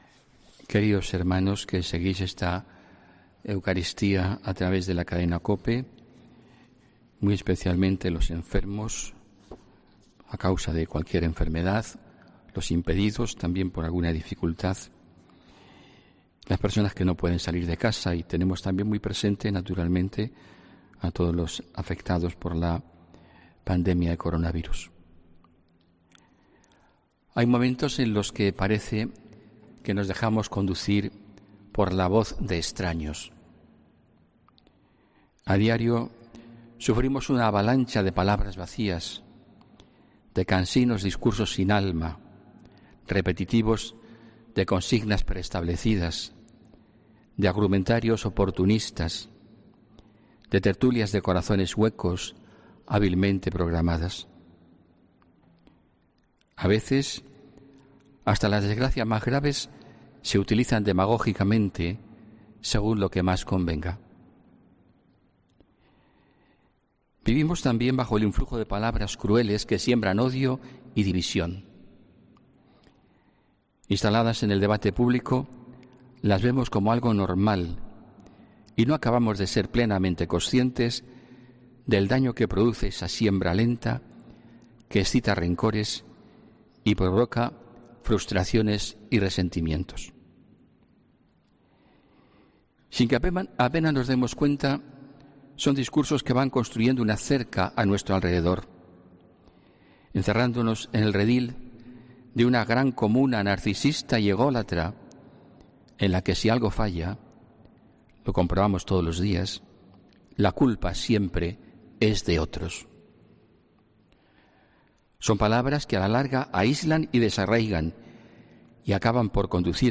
HOMILÍA 3 MAYO 2020